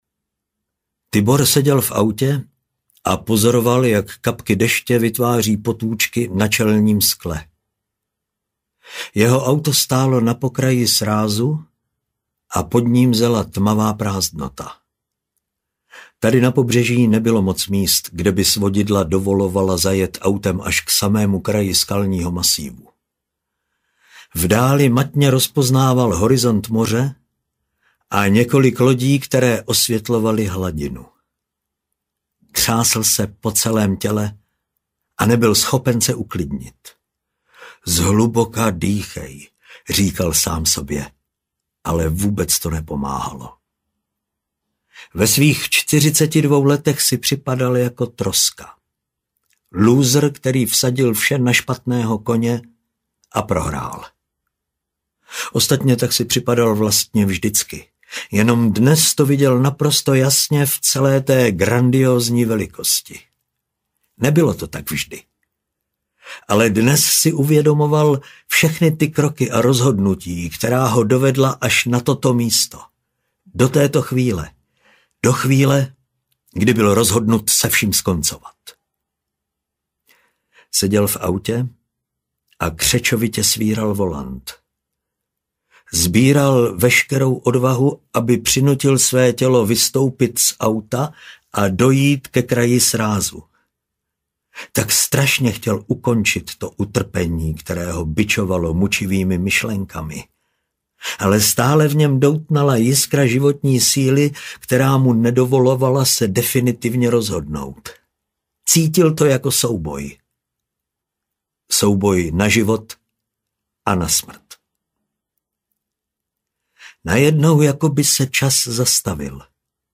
Skok do tmy audiokniha
Ukázka z knihy